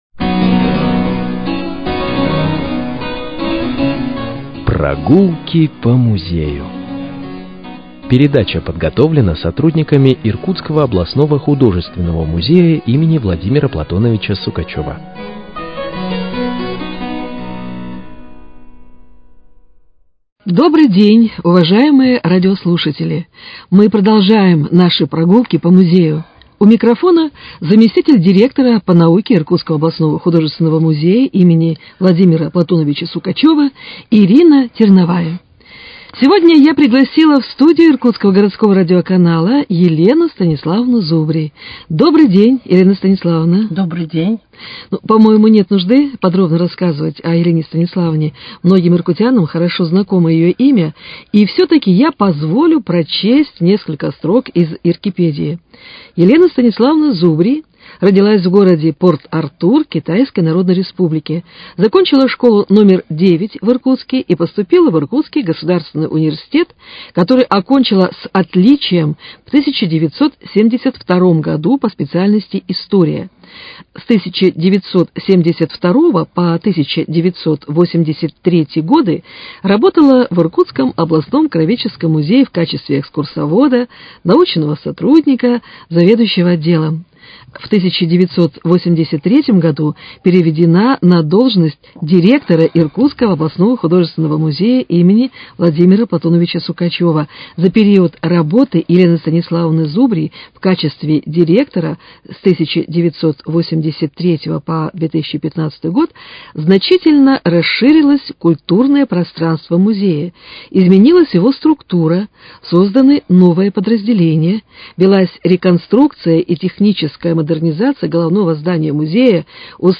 Прогулки по музею: Беседа